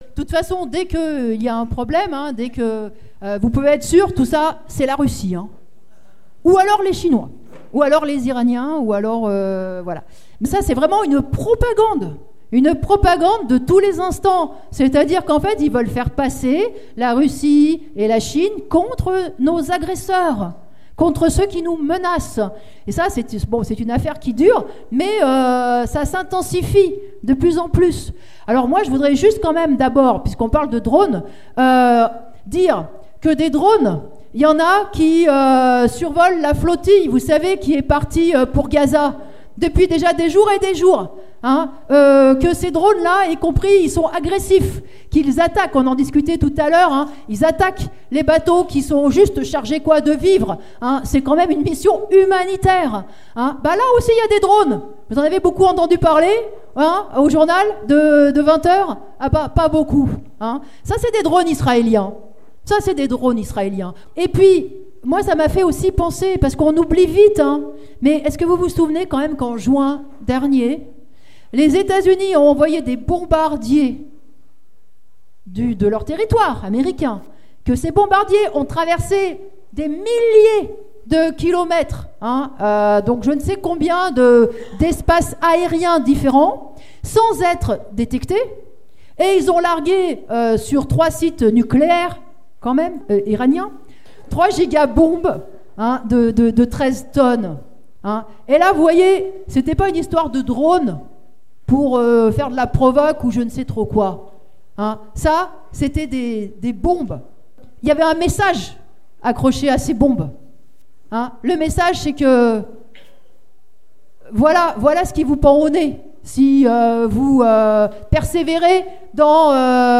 Nathalie Arthaud débat à la fête lyonnaise de LO : Les principaux fauteurs de guerre et de misère sont dans les pays impérialistes